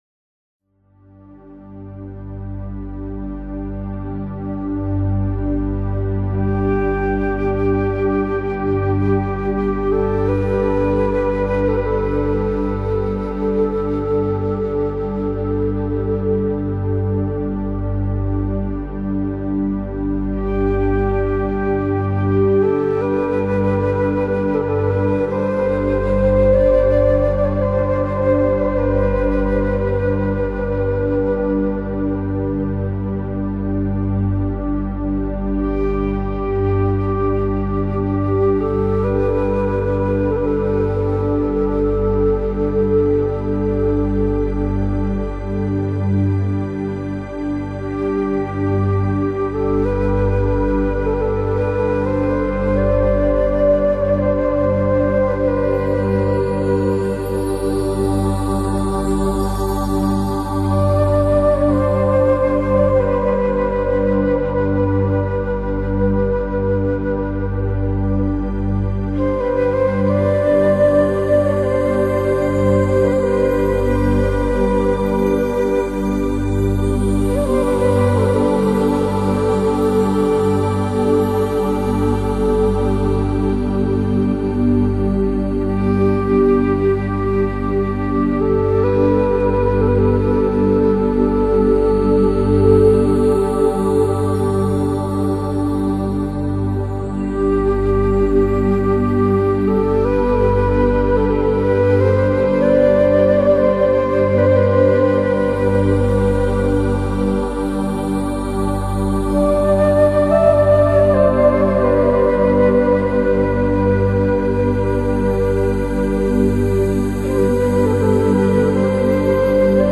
在平静详和的氛围中， 每首乐曲都独具特色，伴随着悠扬的旋律你会忍不住舒展四肢，神思迷离。